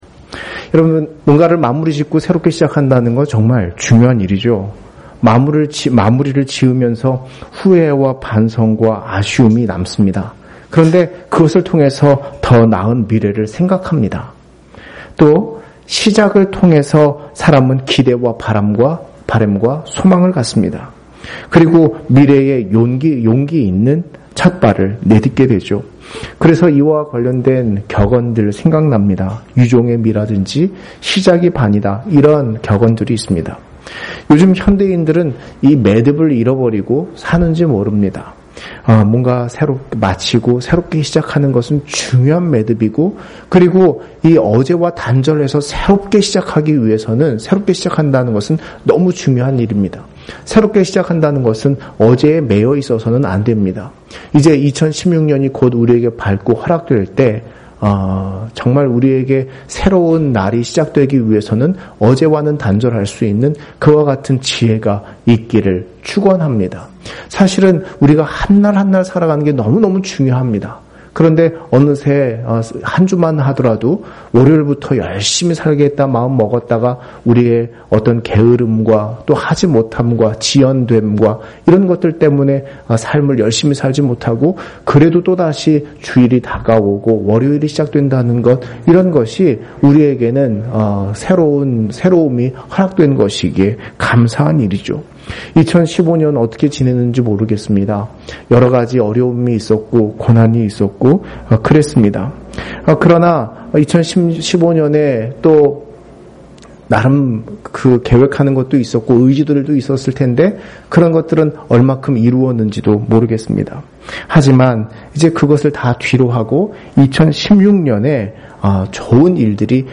송구영신예배_ 복되게 하사 거룩하게 하셨으니(창2:1-4)